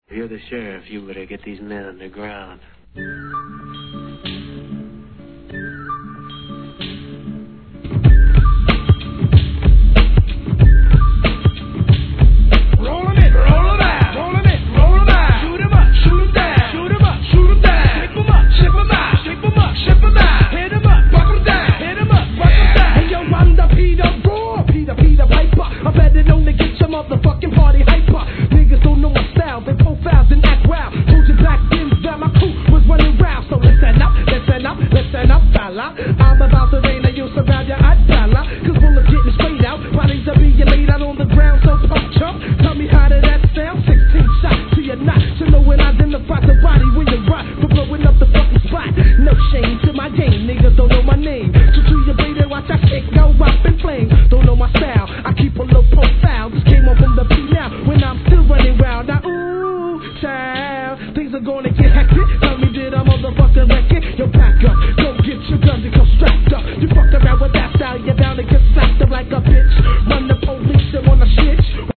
HIP HOP/R&B
勢いあるMICリレーは必聴!!